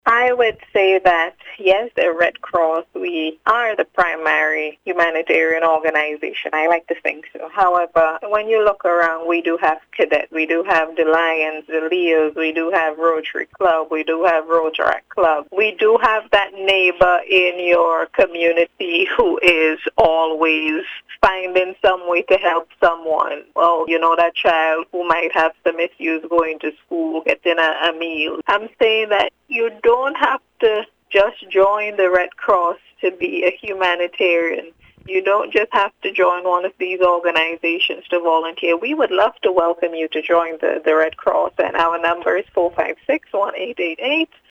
In an interview with NBC News